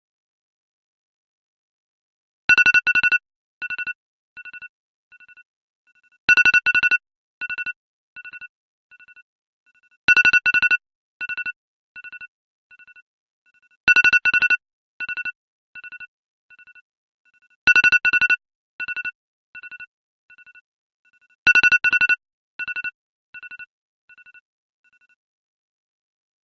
iphone alarm.mp3